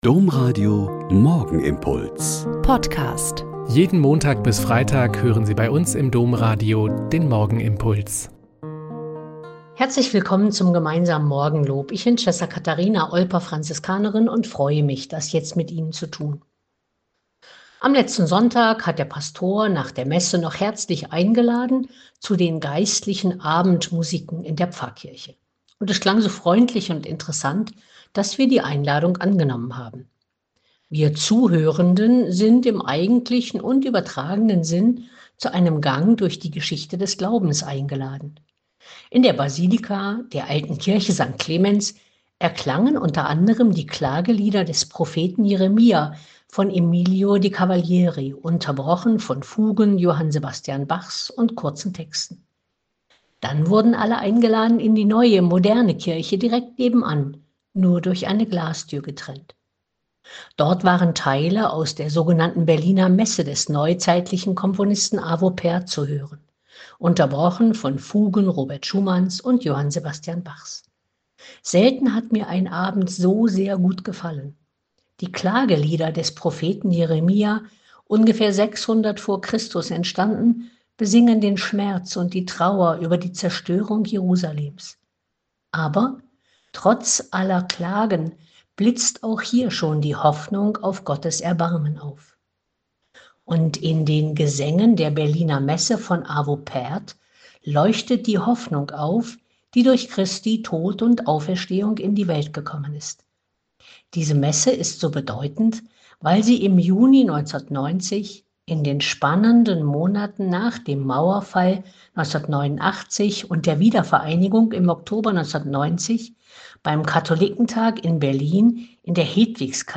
Mt 6,7-15 - Gespräch